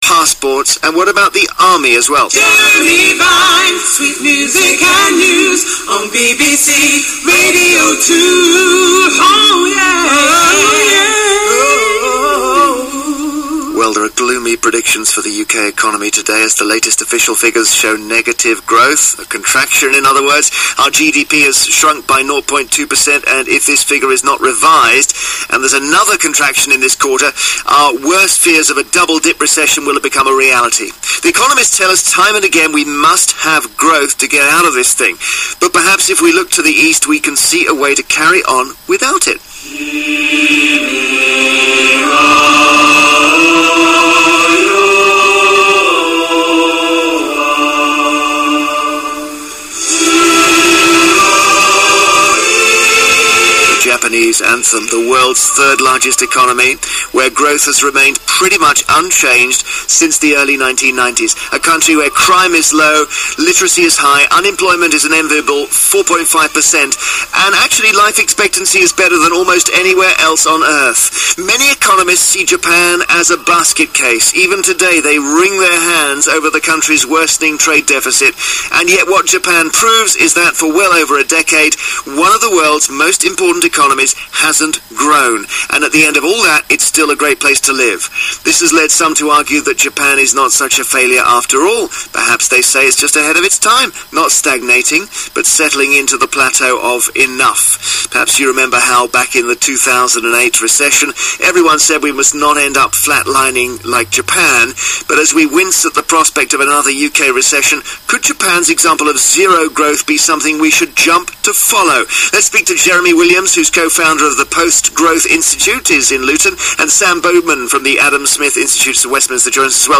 I was on the Jeremy Vine show today on Radio 2 to talk about post growth economics. The production team had come across my article on Japan, and got me in to talk about whether growth is actually necessary in an economy.
growth-debate-radio-2.mp3